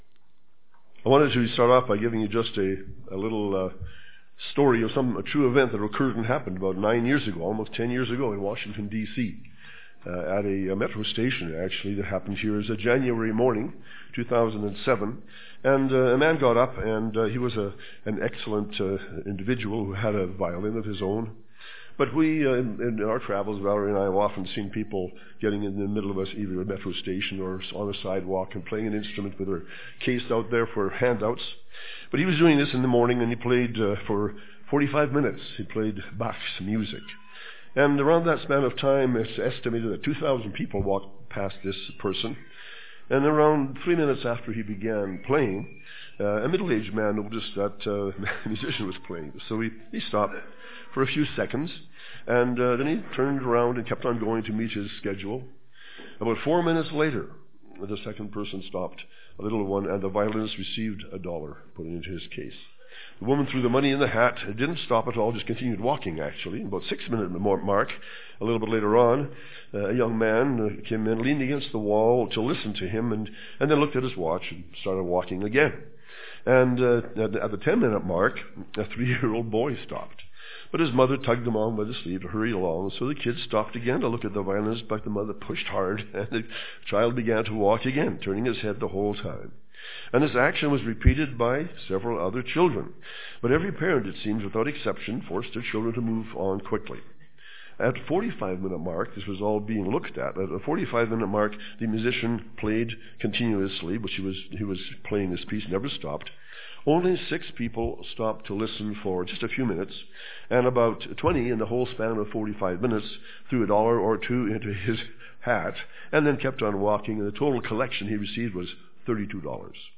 This sermon was given at the Canmore, Alberta 2016 Feast site.